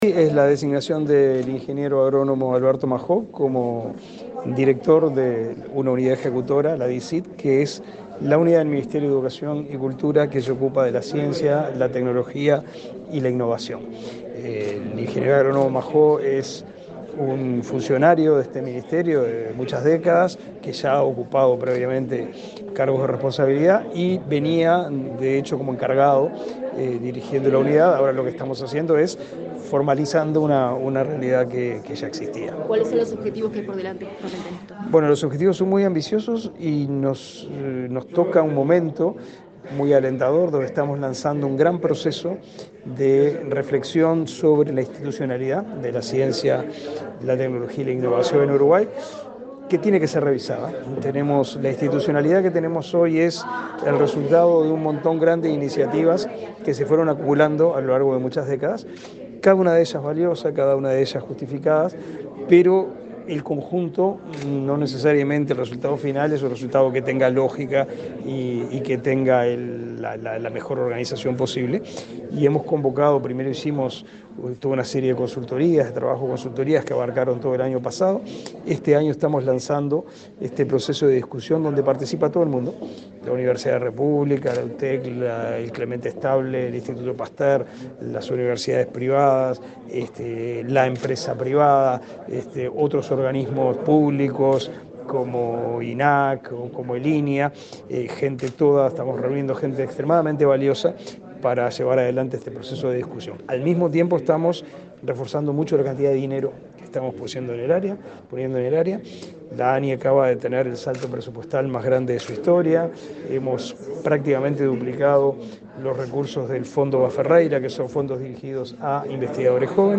Declaraciones del ministro de Educación y Cultura, Pablo da Silveira
Este miércoles 19, el ministro de Educación y Cultura, Pablo da Silveira, asistió, en la sede de esa cartera, a la toma de posesión del cargo del
Luego dialogó con la prensa.